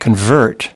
convert-verb.mp3